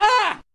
shock.ogg